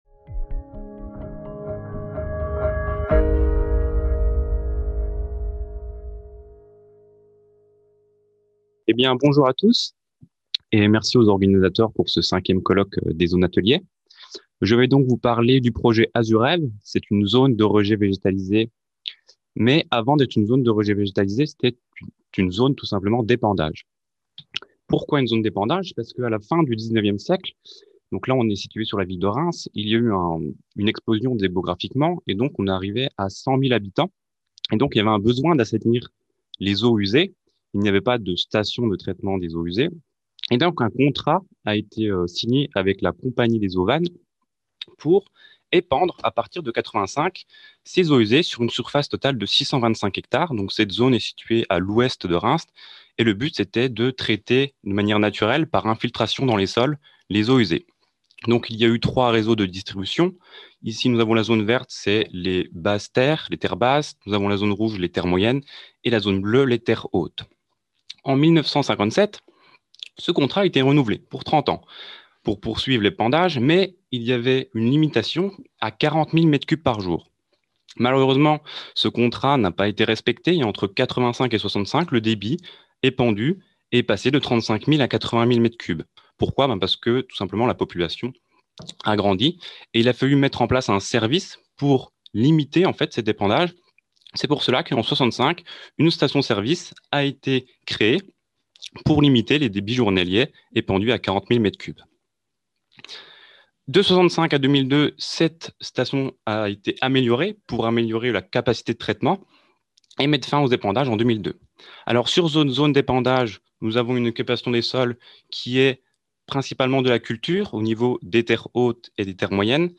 5e colloque des Zones Ateliers – CNRS 2000-2020, 20 ans de recherche du Réseau des Zones Ateliers